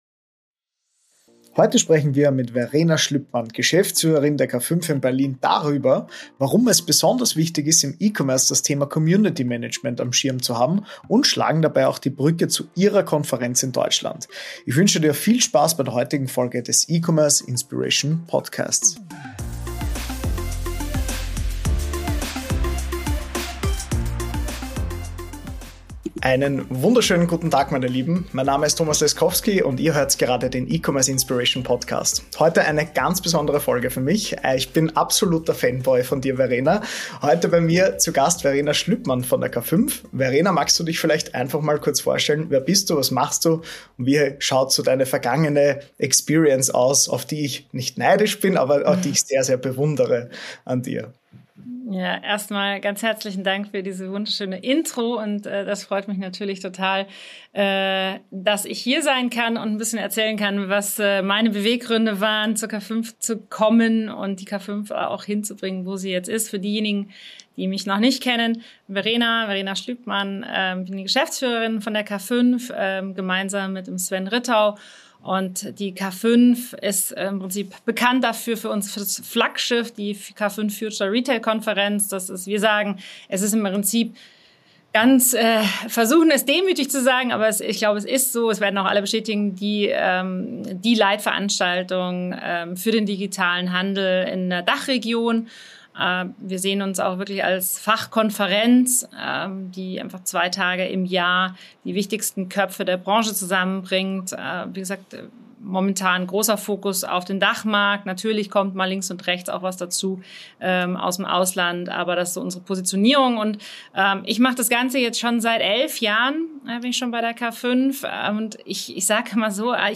Ein inspirierendes Gespräch über Leidenschaft, Purpose und die Kraft des Zuhörens.